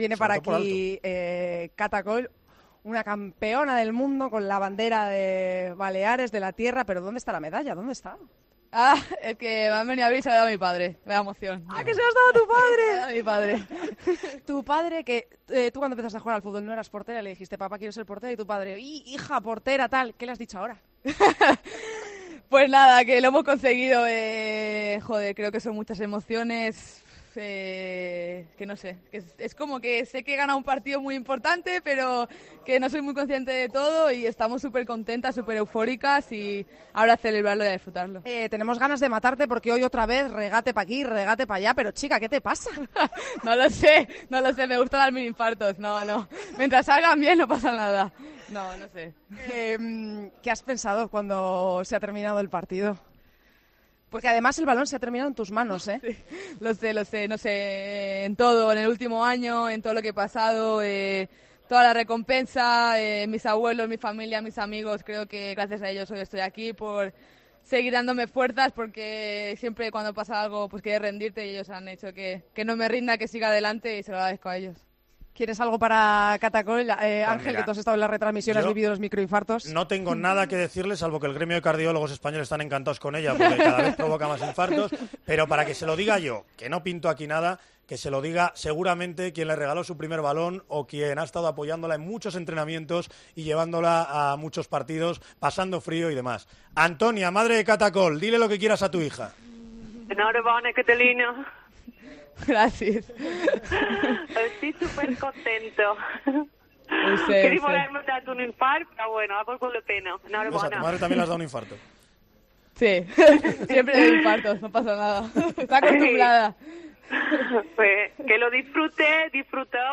Catalina Coll, jugadora de la Selección española atendió a los micrófonos de Tiempo de Juego en la zona de prensa.